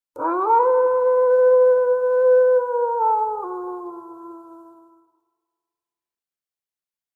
Download Wolf Howling sound effect for free.
Wolf Howling